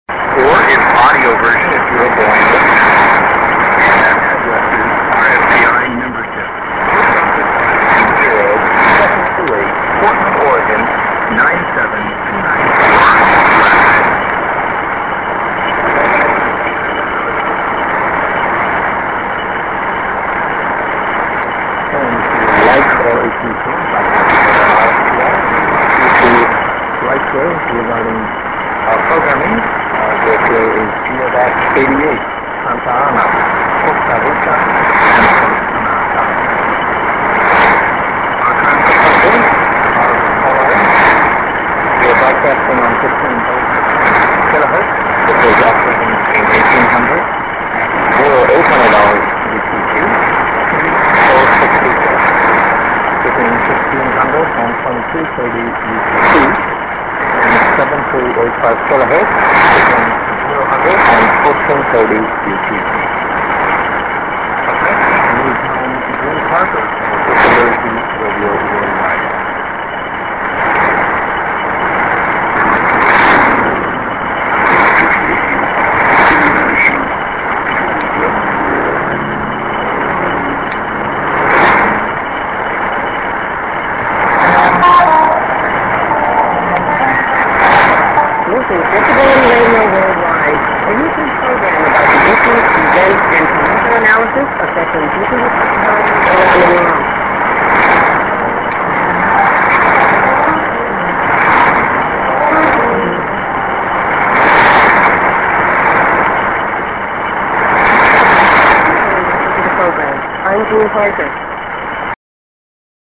ANN(man)->30":ADDR=RFPI(Costa Rica)+SKJ(man)+ID->1'28":ID(woman)